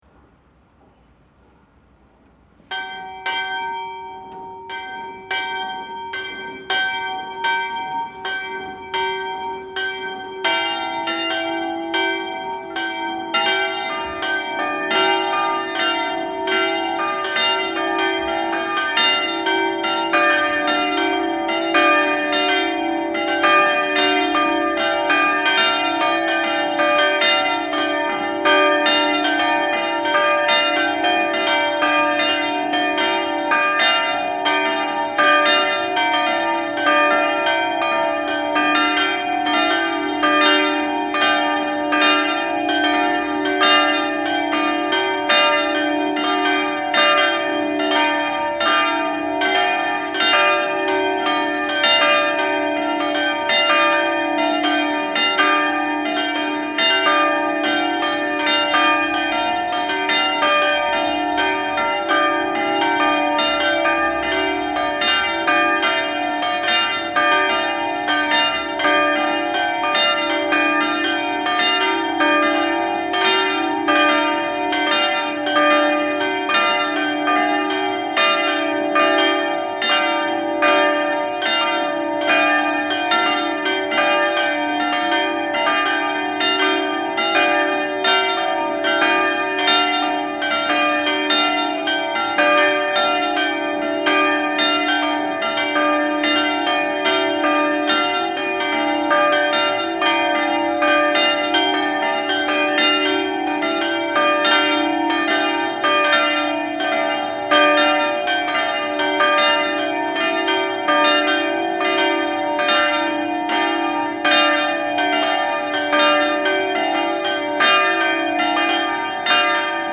Grensáskirkja - Kirkjuklukkur Íslands
grensaskirkja_allar.mp3